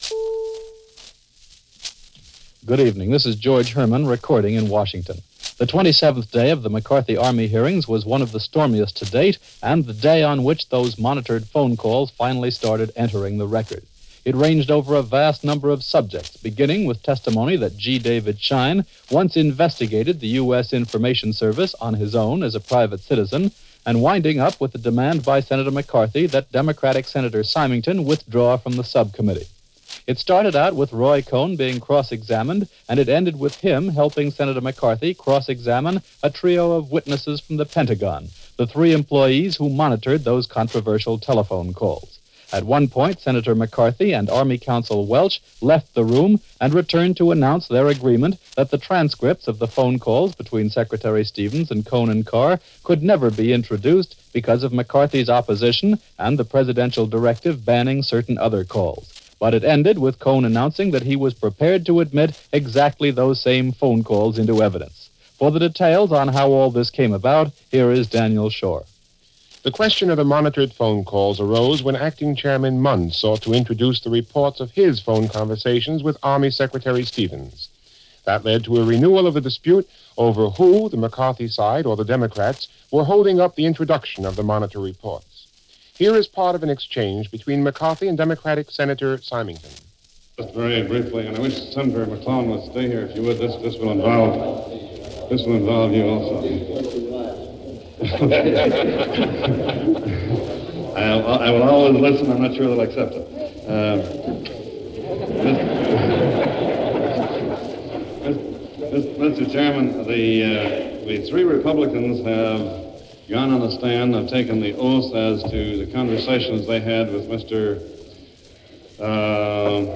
When Hearings Go Public - Army-McCarthy - 1954 - Recap of the days proceedings, June 4, 1954 - Past Daily Weekend Reference Room.